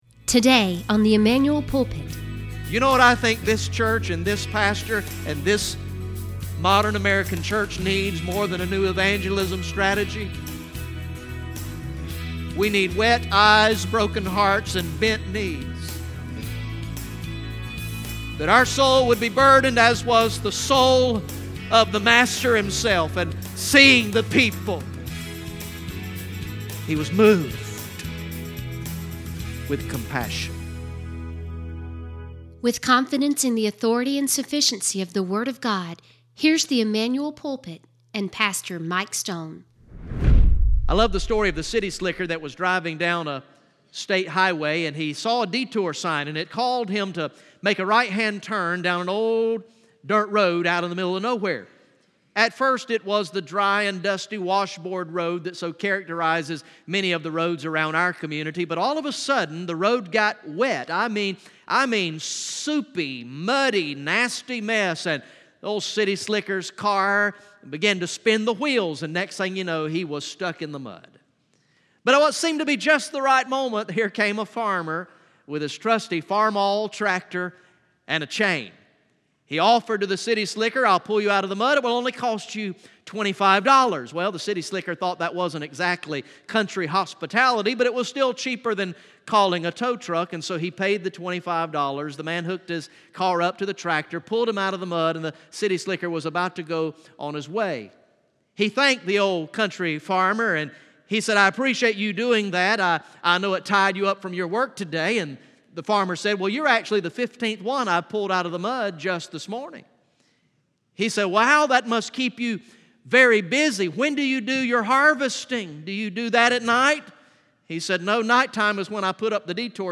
From the morning worship service on Sunday, September 16, 2018